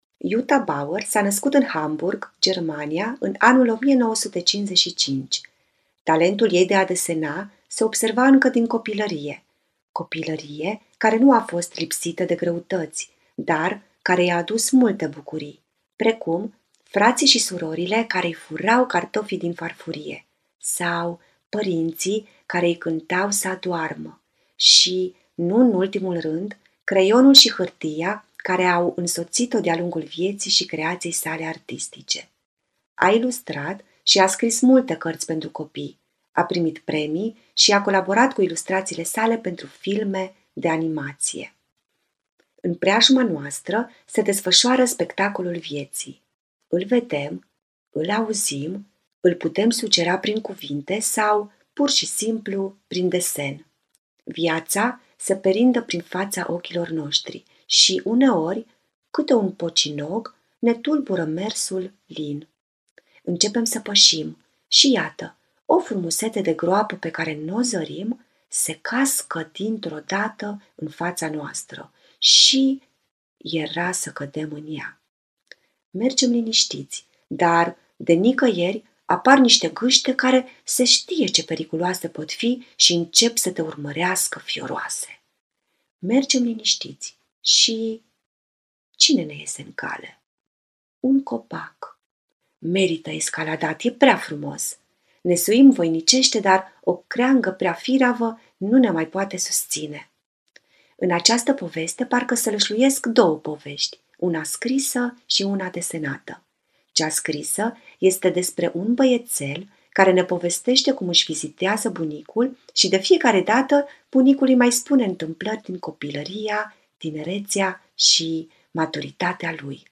actor păpușar